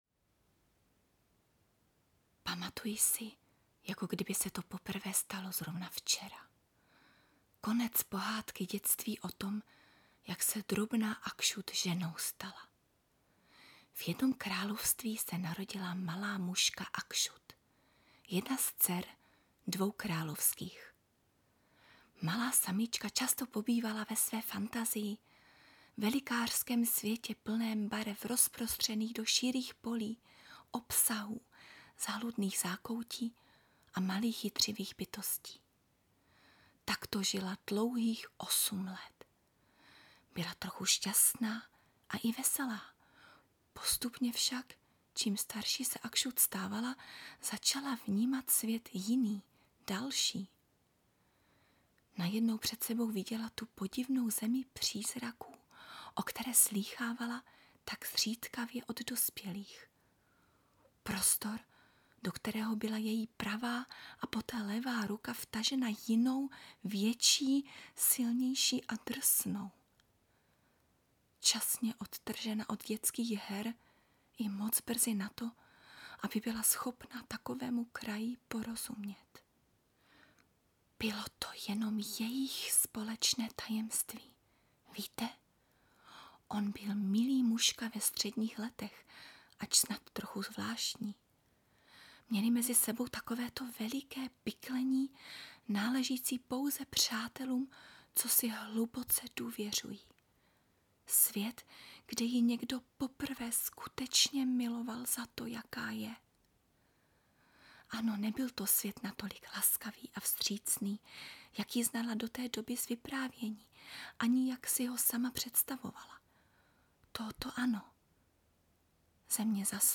Rodil� mluv�� Brno - n�m�ina a �e�tina, �innost od roku 1995. Koment��e, mluven� slovo, audio nahr�vky, dabing v n�meck�m jazyce.